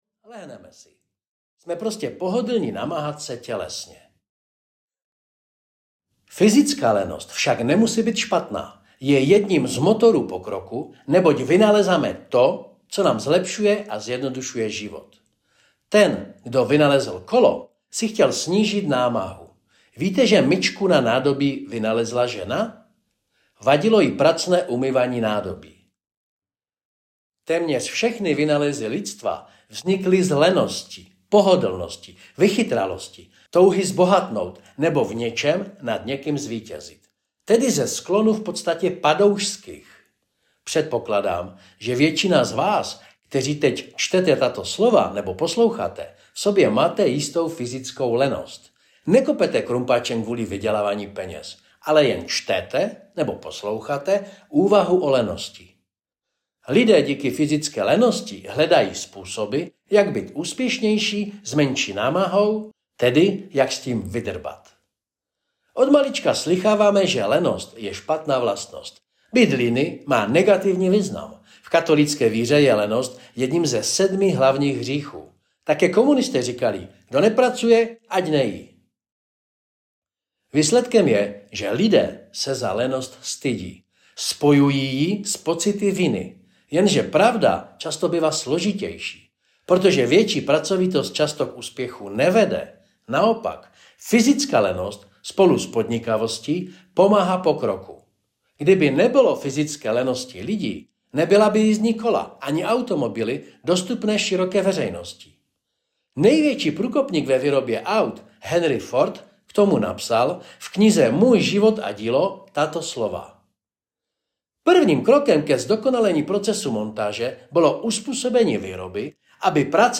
Duševní lenost aneb Když myšlení bolí audiokniha
Ukázka z knihy